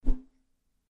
Curtain closing.mp3